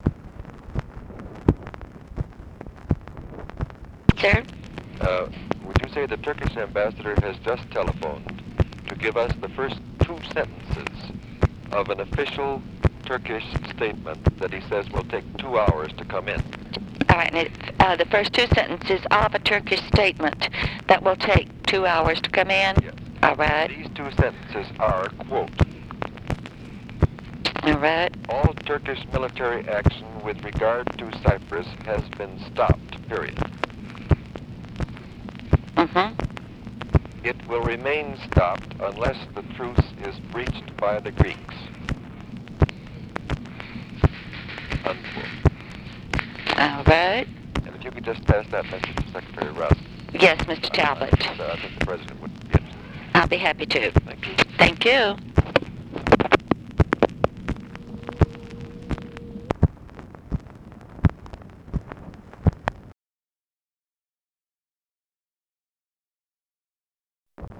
Conversation with PHILLIPS TALBOT
Secret White House Tapes